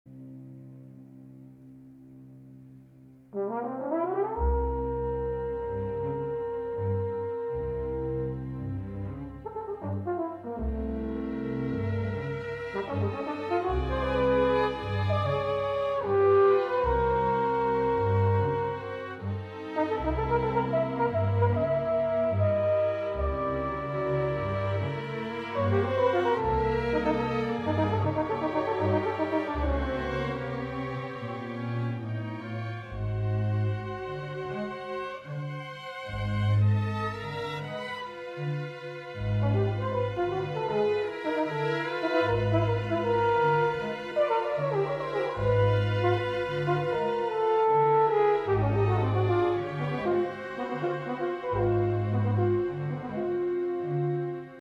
Recorded Vilnius, Lithuania October 10-11, 1995